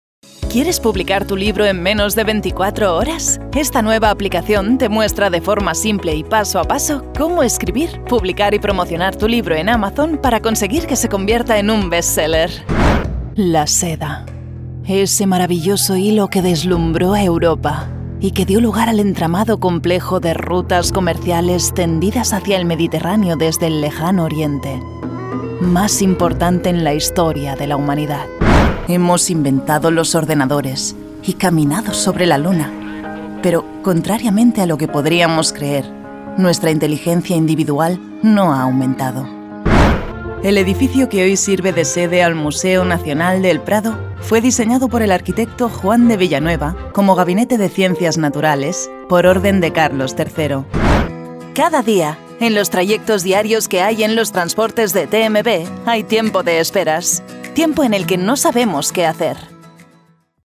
Warm, Soft, Natural, Friendly, Young
Corporate